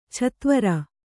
♪ chatvara